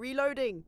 Voice Lines / Barklines Combat VA
Becca Reloading.wav